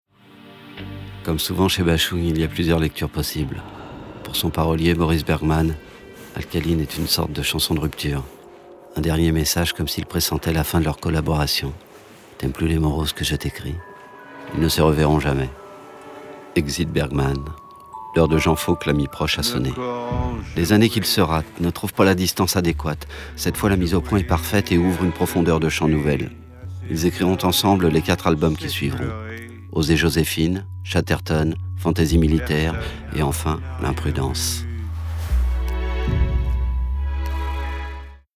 Extrait voix doc